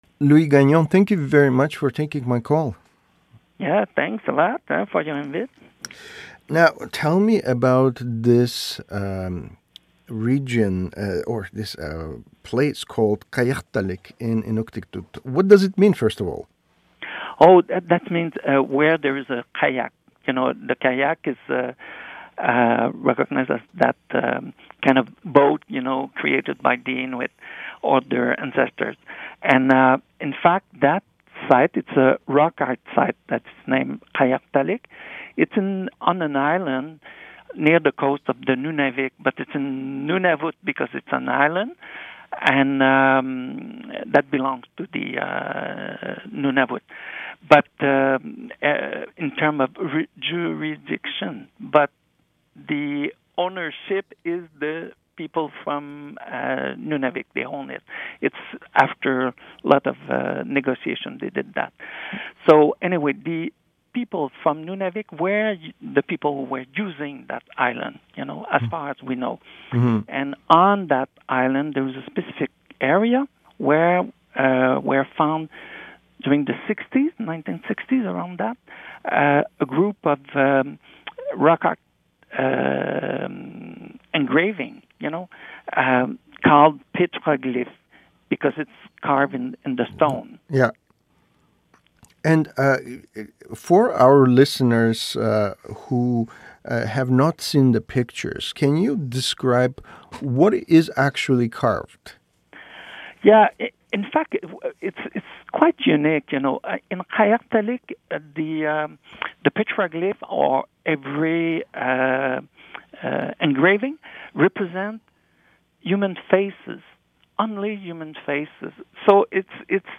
Feature interview